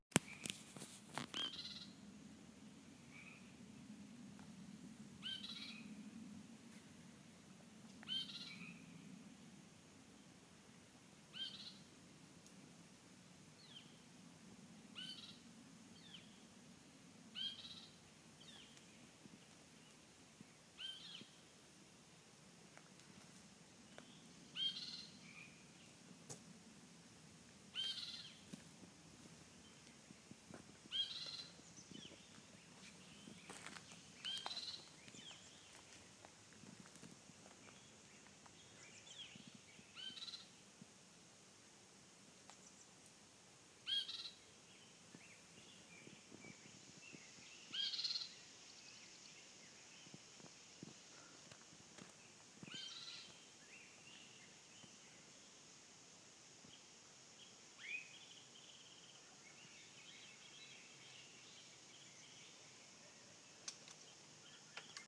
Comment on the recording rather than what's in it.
eBird — Redwood Regional Park–Skyline Gate area — May 18, 2017